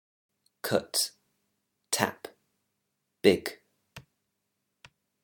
vowel + single consonant at the end = short vowel sound